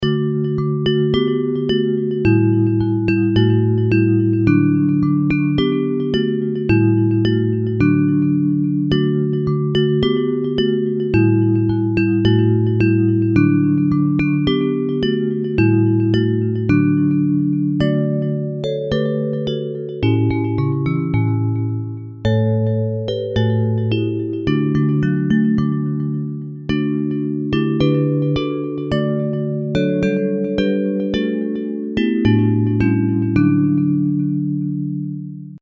Hymns of praise
Bells